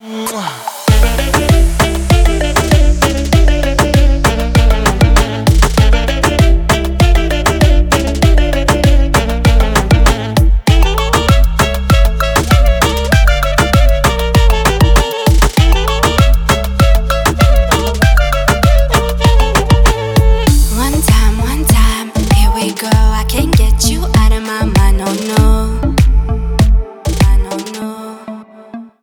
• Качество: 320, Stereo
поп
женский вокал
dance
Dance Pop
балканские